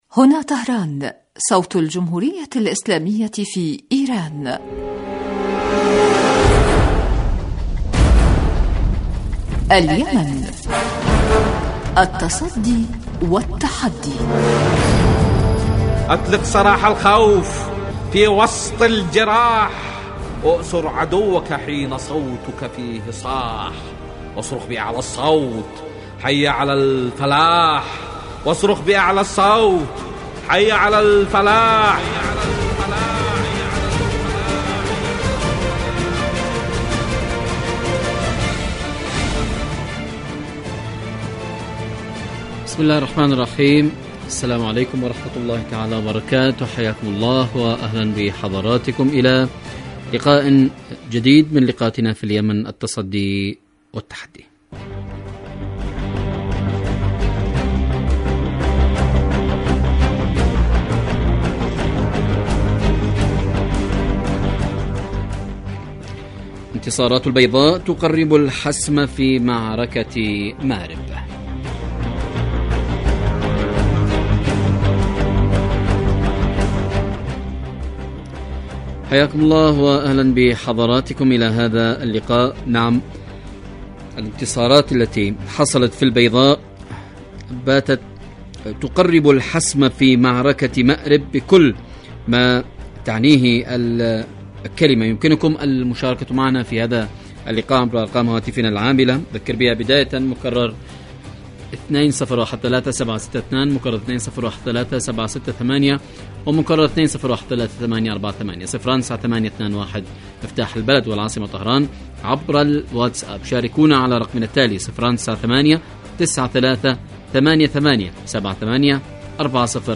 برنامج سياسي حواري يأتيكم مساء كل يوم من إذاعة طهران صوت الجمهورية الإسلامية في ايران
البرنامج يتناول بالدراسة والتحليل آخر مستجدات العدوان السعودي الأمريكي على الشعب اليمني بحضور محللين و باحثين في الاستوديو